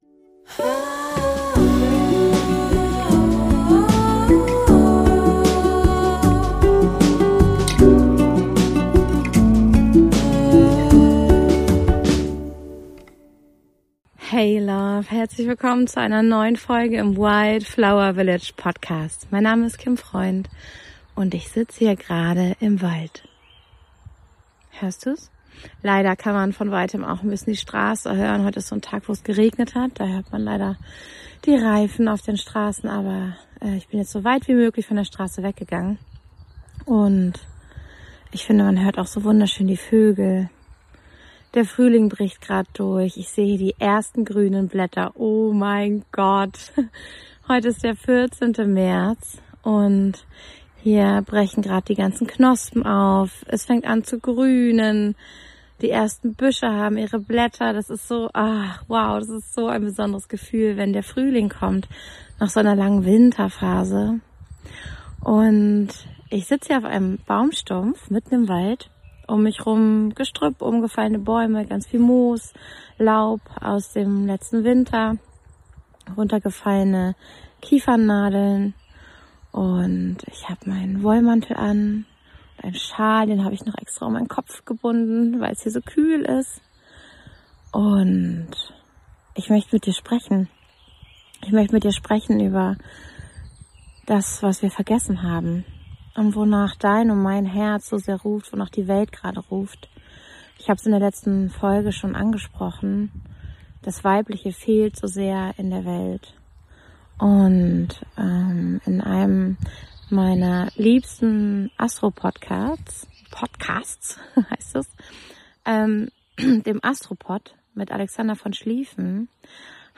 Inmitten des Frühlingserwachens im Wald